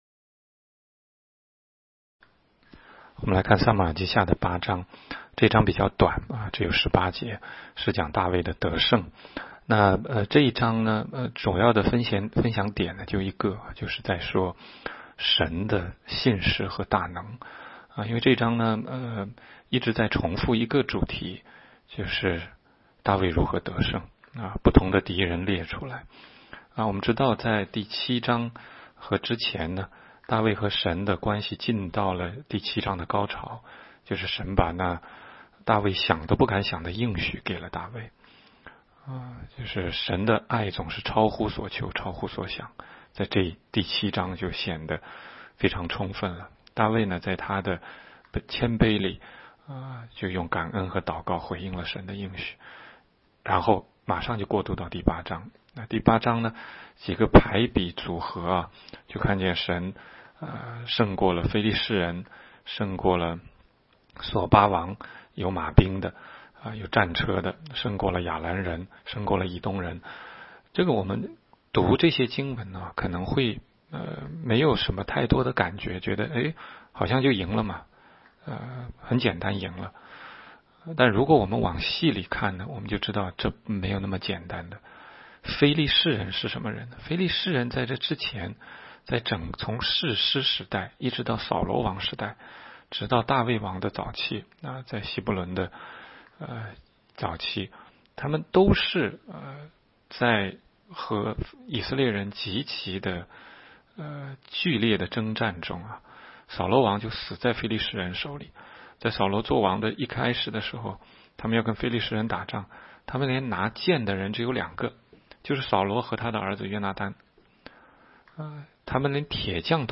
16街讲道录音 - 每日读经-《撒母耳记下》8章